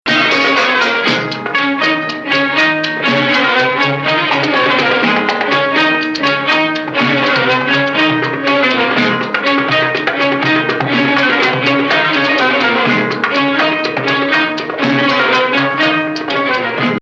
Rast 4 low